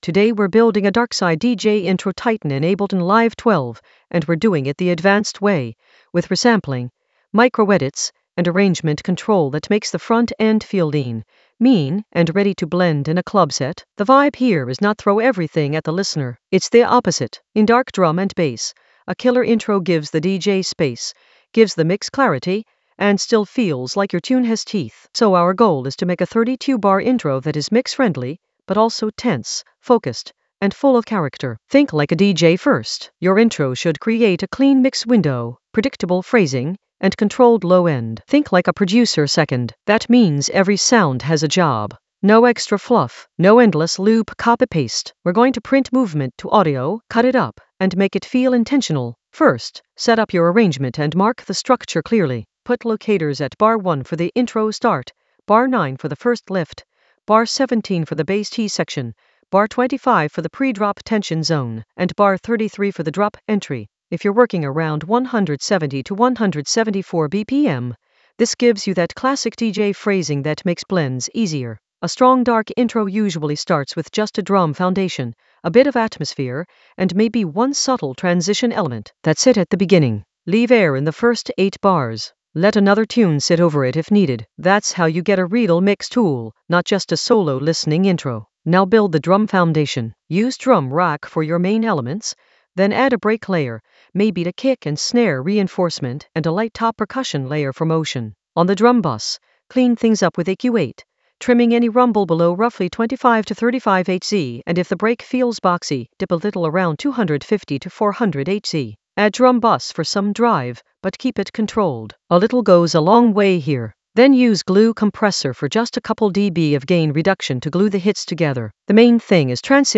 An AI-generated advanced Ableton lesson focused on Darkside approach: a DJ intro tighten in Ableton Live 12 in the Resampling area of drum and bass production.
Narrated lesson audio
The voice track includes the tutorial plus extra teacher commentary.